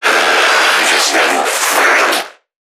NPC_Creatures_Vocalisations_Infected [46].wav